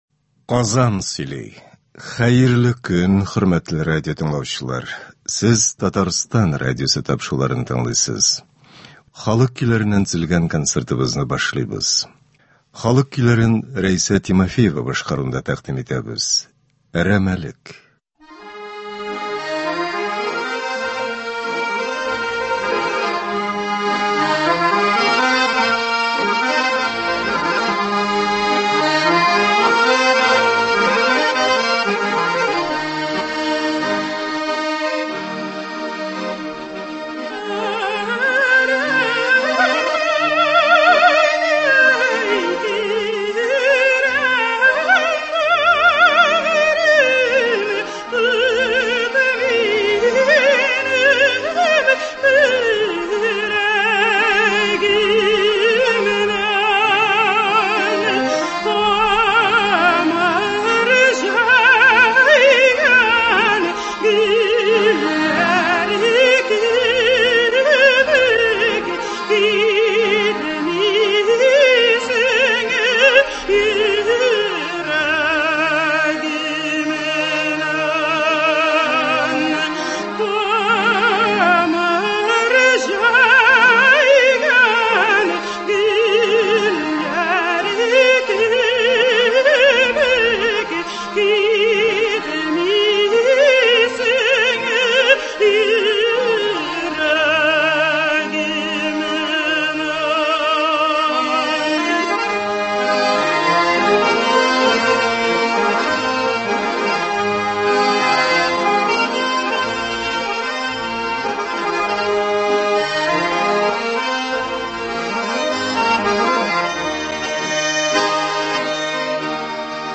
Татар халык көйләре (10.09.22)